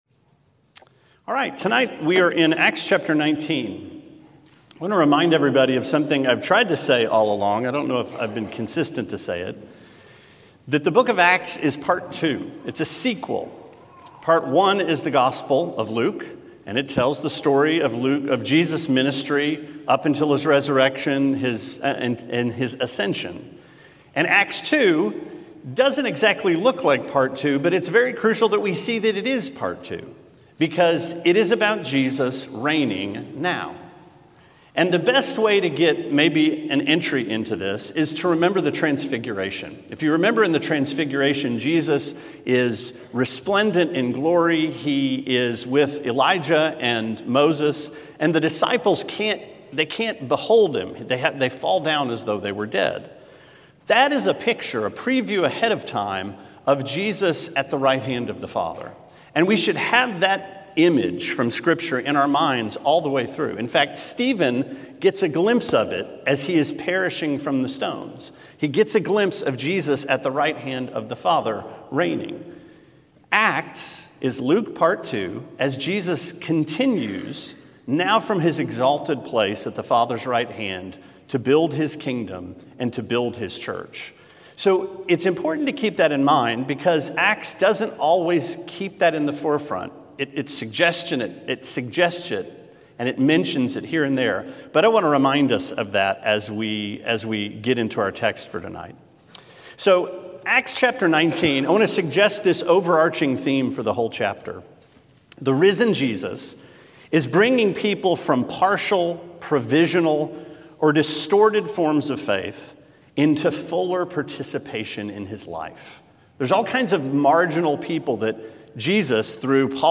Sermon 3/20: Acts 19: Ever Deepening Life